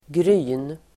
Ladda ner uttalet
gryn substantiv, grain Uttal: [gry:n] Böjningar: grynet, gryn, grynen Definition: skalat korn av säd Avledningar: grynig (grainy) Sammansättningar: havregryn (rolled oats) grain substantiv, korn , gryn , smula grains , gryn